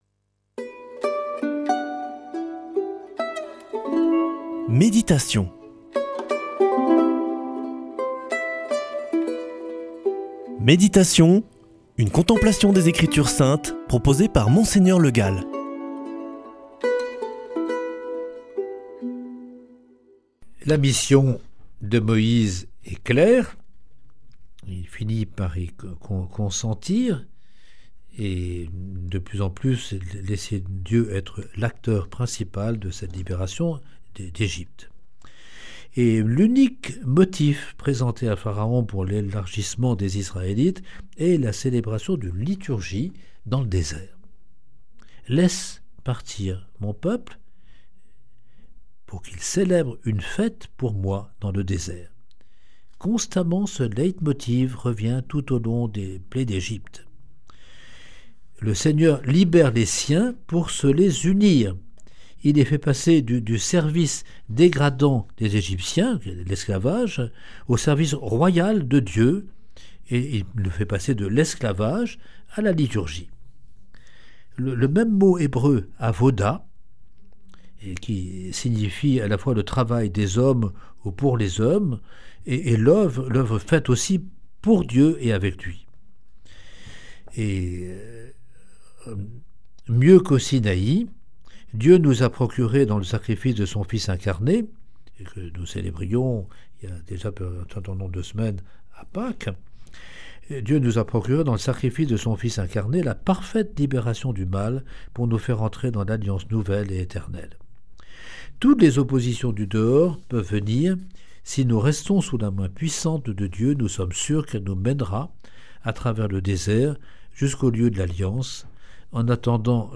lundi 30 juin 2025 Méditation avec Monseigneur Le Gall Durée 7 min
Une émission présentée par